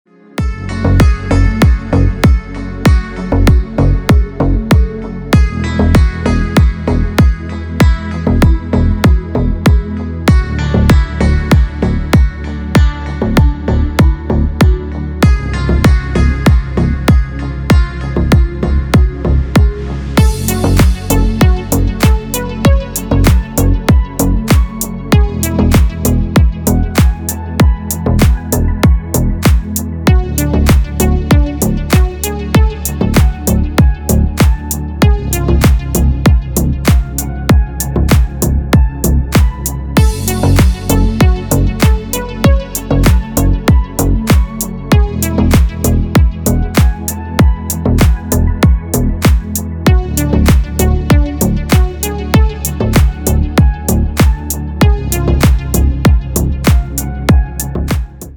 Приятный саунд на мобилу